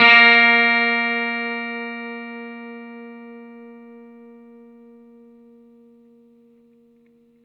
R12NOTE BF+.wav